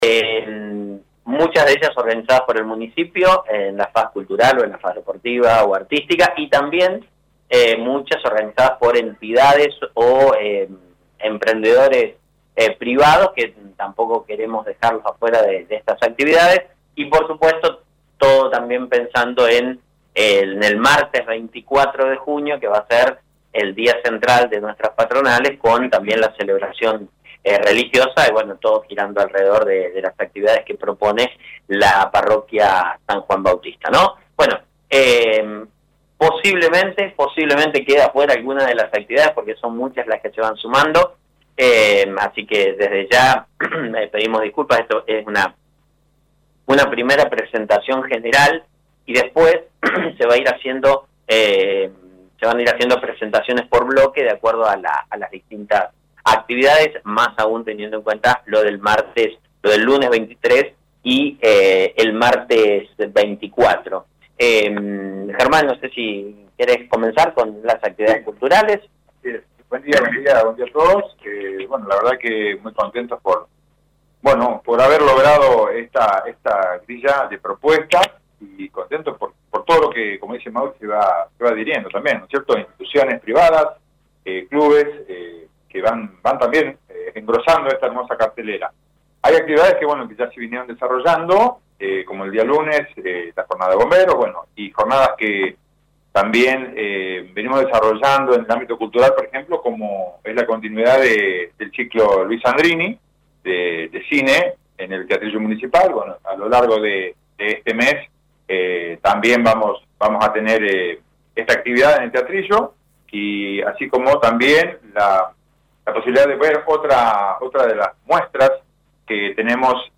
En conferencia de prensa, el Municipio presentó la grilla de actividades a realizarse durante el mes de junio en el marco de las Fiestas Patronales en honor a San Juan Bautista.